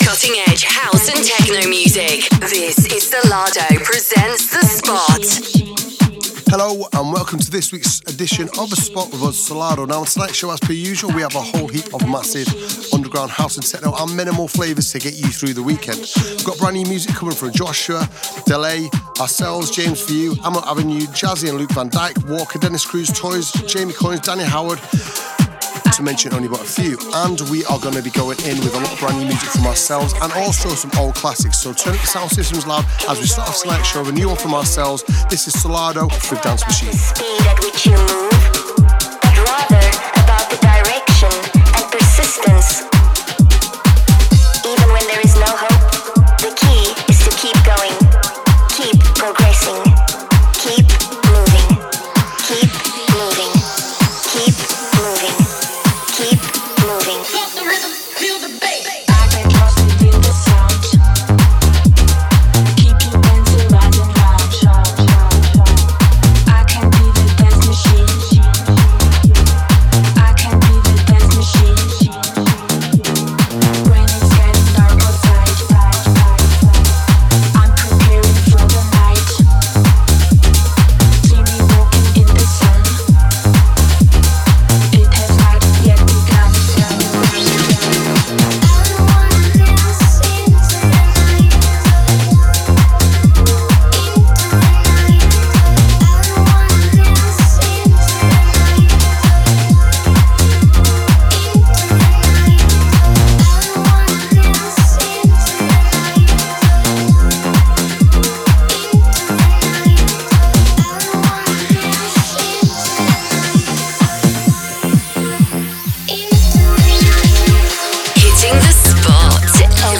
cutting edge house & techno from around the globe
exclusive live mixes and guest appearances